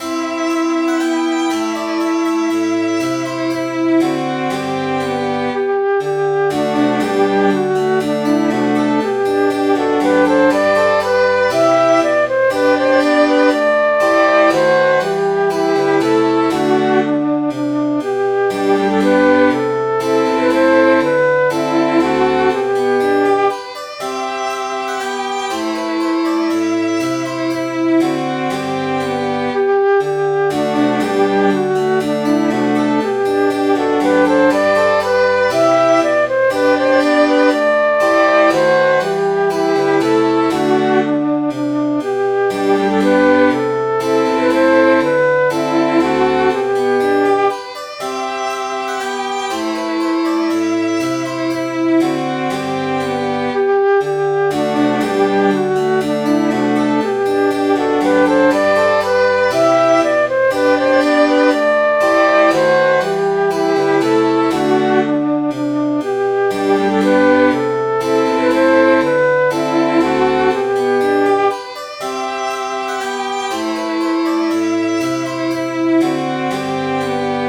Midi File, Lyrics and Information to Hares on the Mountains